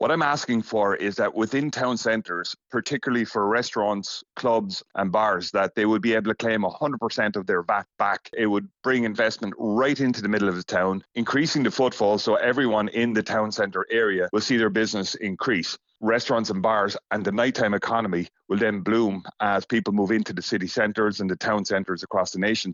Councillor Michael Sheehan explains how the zones would increase population numbers